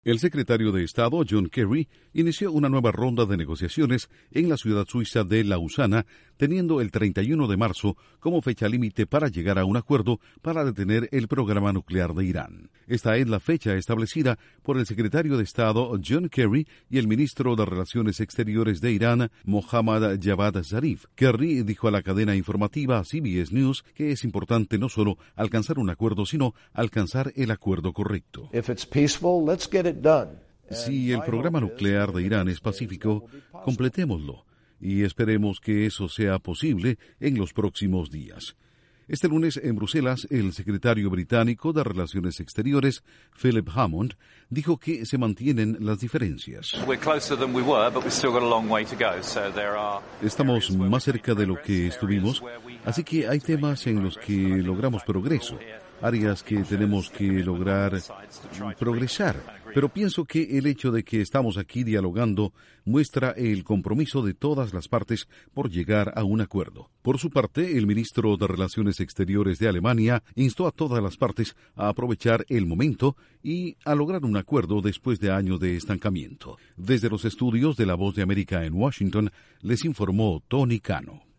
Enviados de Estados Unidos e Irán se reúnen en Lausana mientras se acerca la fecha límite para alcanzar un acuerdo nuclear. Informa desde los estudios de la Voz de América en Washington